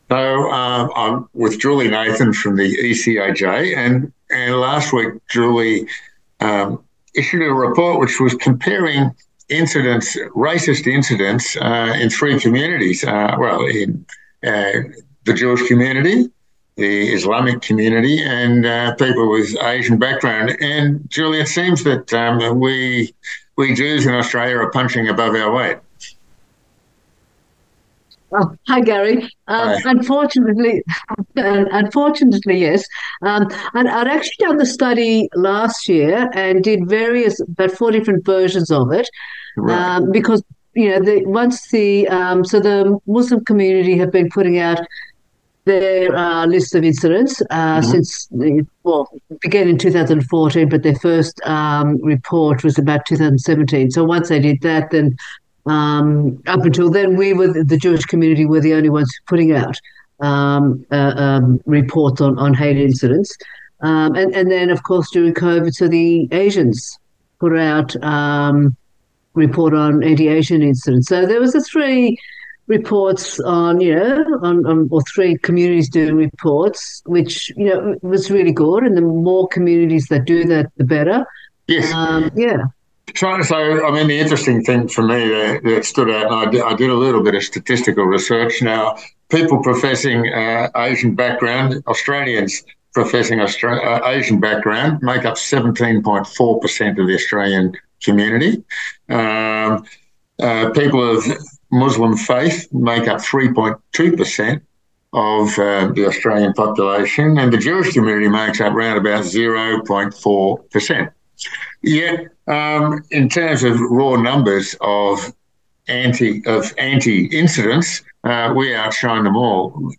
J‑Air interview on hate incidents in Australia - ECAJ